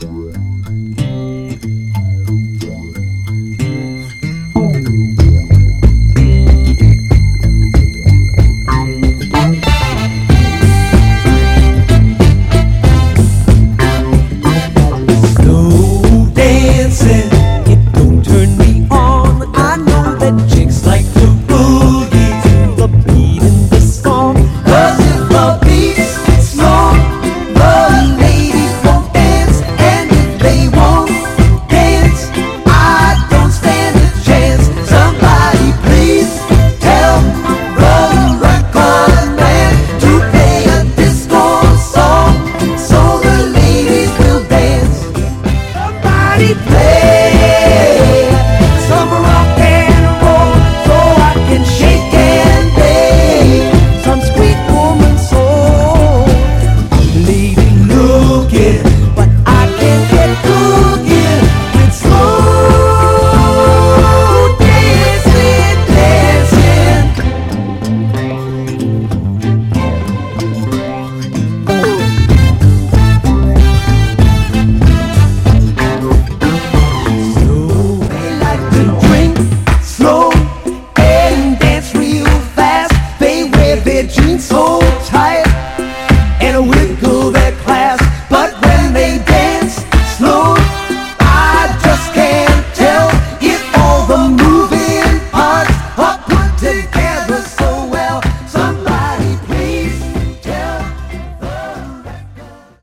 曲名通り、グイグイと腰を直撃する様なスロウなファンク…